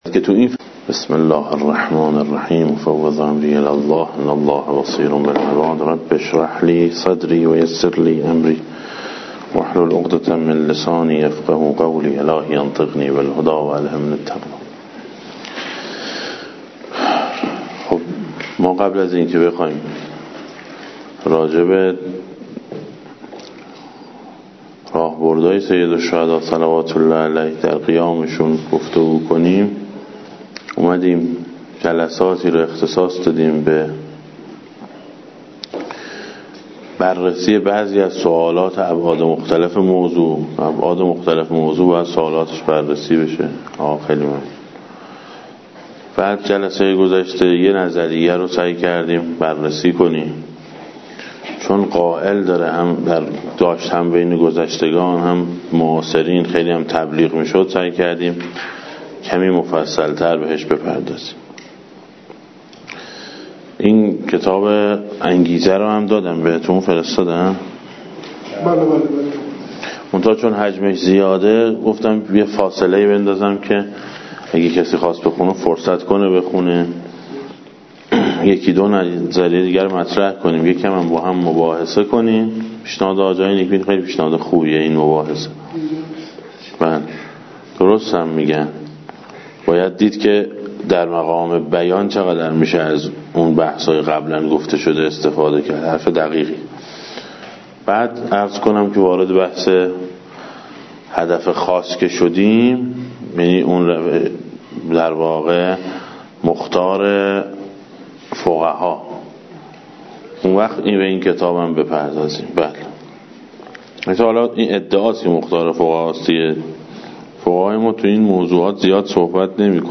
در حوزه مروی روز یکشنبه 09 آبان 1395 برگزار شد که مشروح این کلاس تقدیم می گردد.